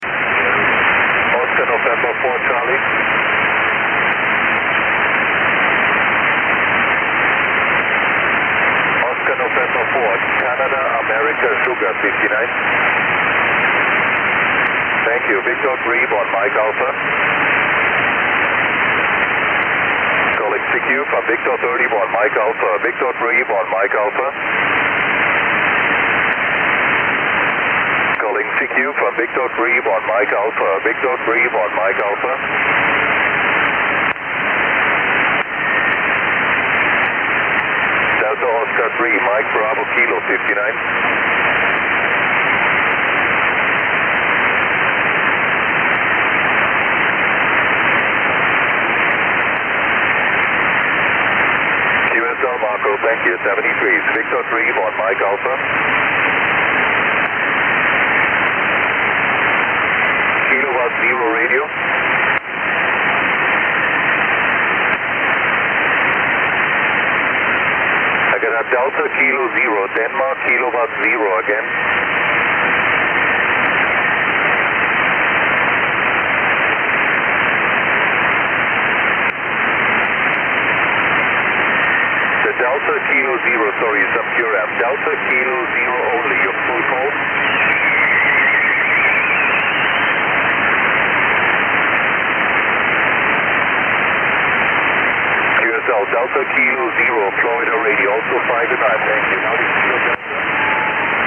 V31MA 10mt SSB